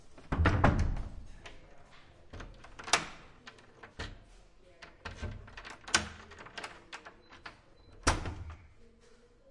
描述：敲门声的关键声音。
Tag: 按键 开锁